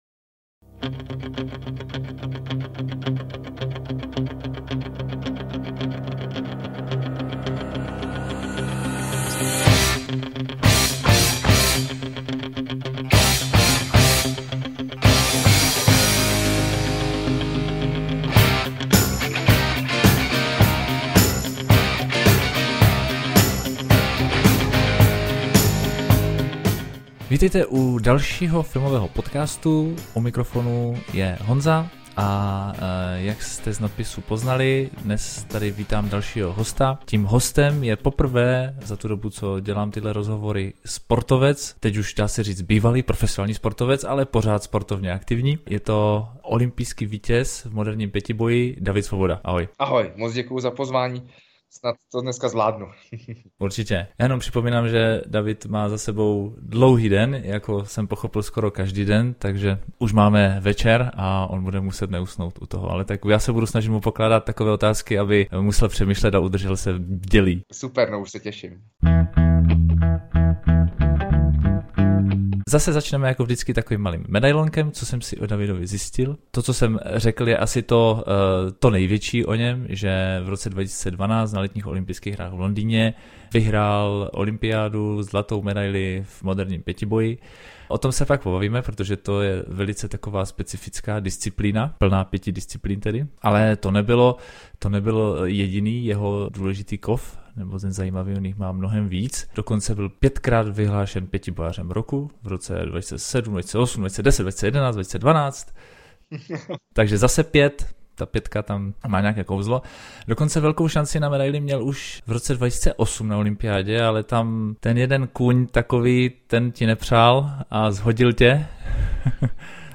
Jsem rád, že pozvání k rozhovoru přijal David Svoboda.